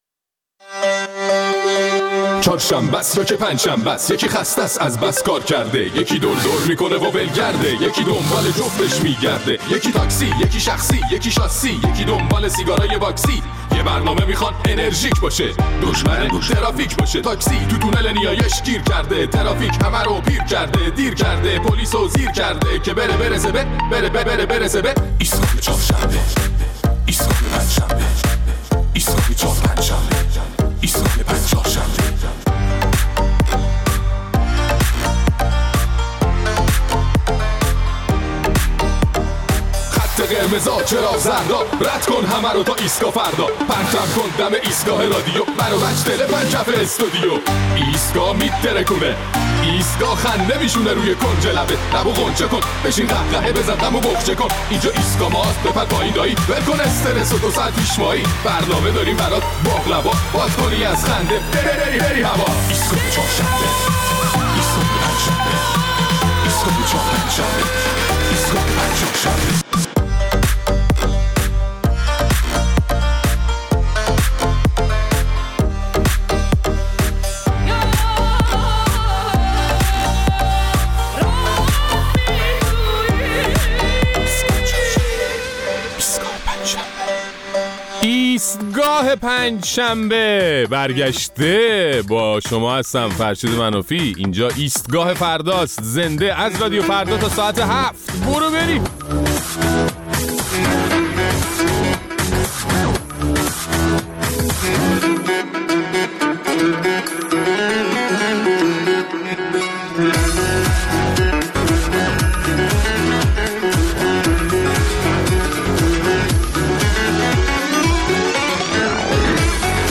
در این برنامه ادامه نظرات شنوندگان را درباره واکنش‌ و نوع برخودشان با مسئولین در صورت مواجهه حضوری با آنها می‌شنویم.